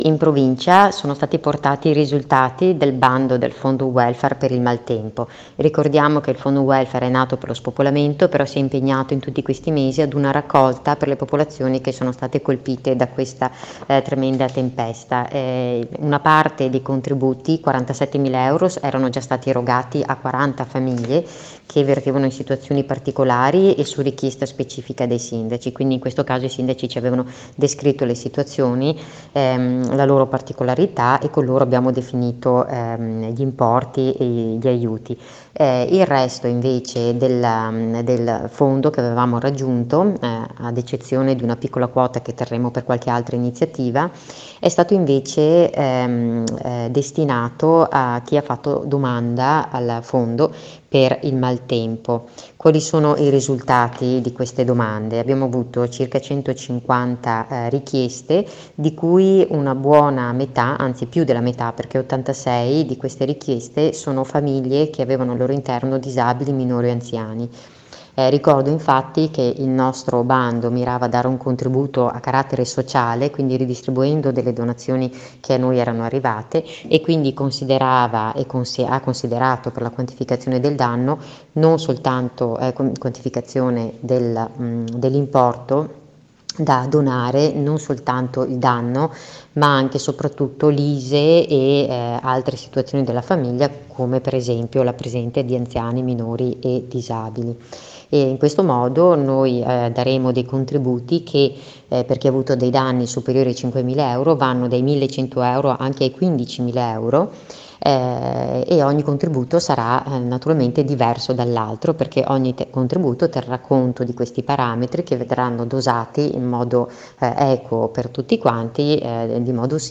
AI MICROFONI DI RADIO PIU’